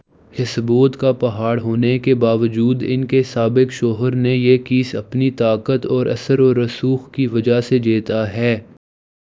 deepfake_detection_dataset_urdu / Spoofed_TTS /Speaker_06 /261.wav